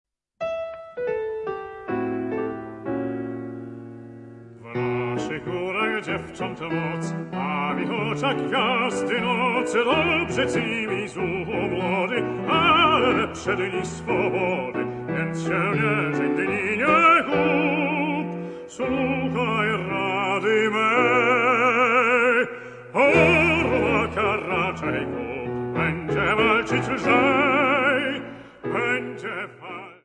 Fortepian